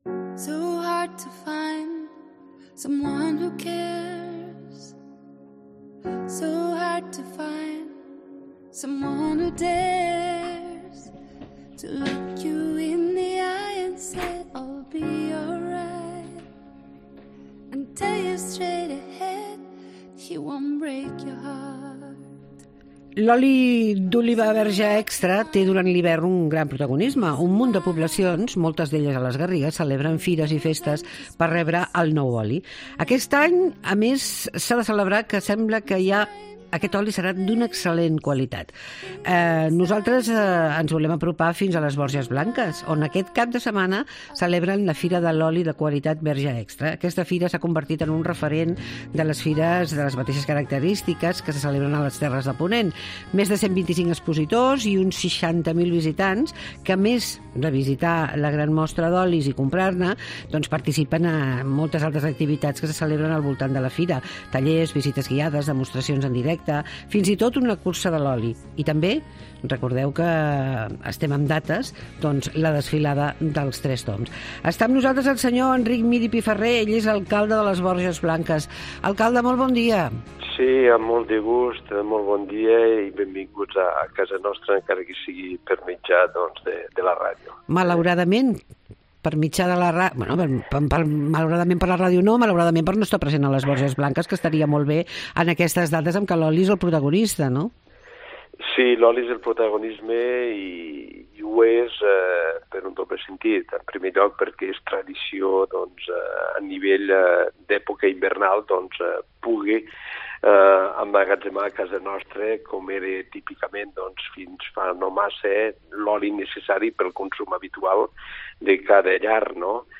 AUDIO: L'alcalde de Les Borges Blanques ens convida a la Fira de l'oli